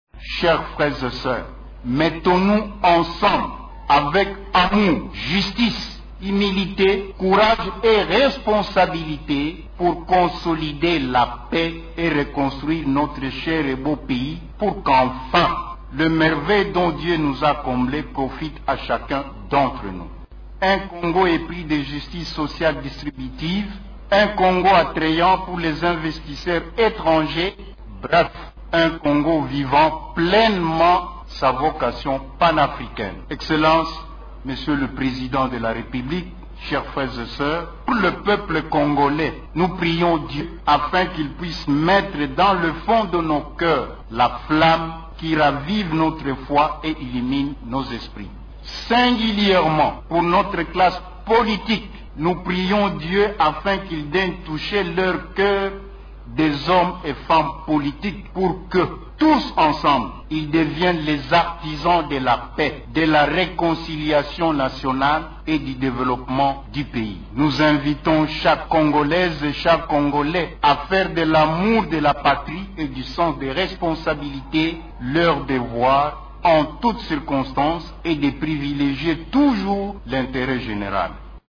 Un culte œcuménique a été organisé samedi 16 janvier 2021 au mausolée Laurent-Désiré Kabila en face du Palais de la Nation à Kinshasa, a l’occasion du vingtième anniversaire de l’assassinat d’ex-chef d’Etat congolais.